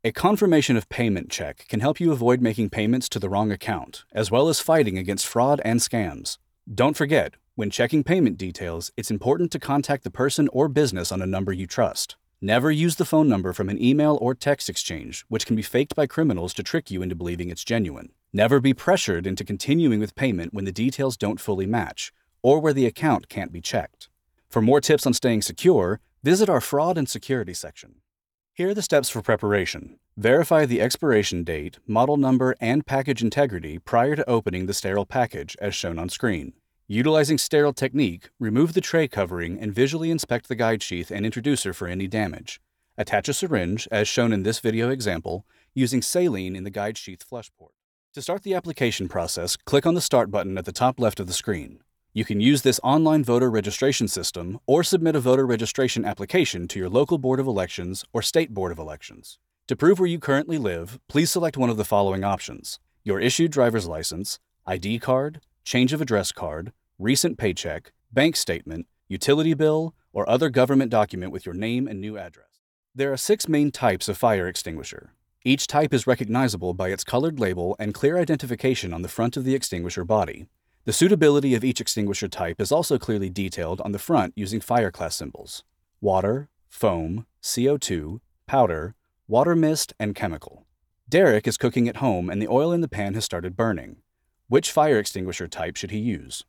Professional male voiceover artist for your next project!
eLearning Demo
My broadcast-quality home studio setup includes:
• Microphone: AKG Pro C214 XLR
• Sound Treatment: 2-inch acoustic foam panels and bass traps